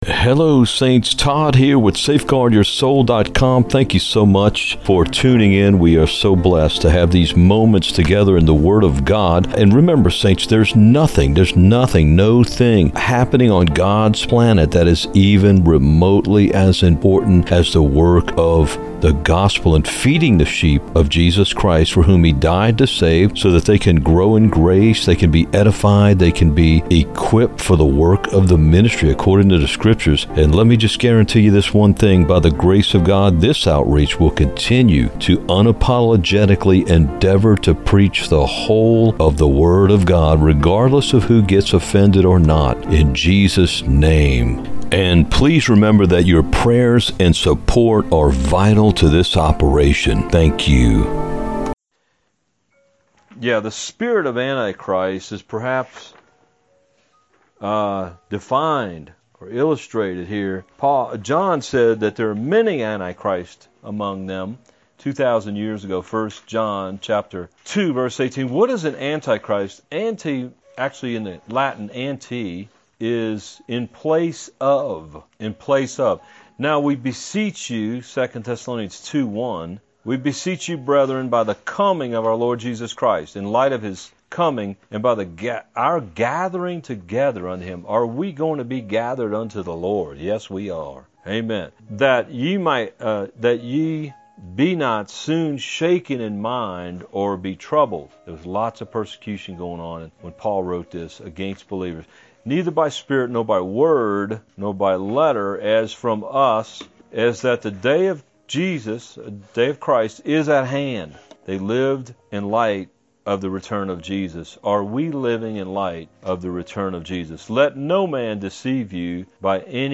Recorded at a fellowship in Colorado.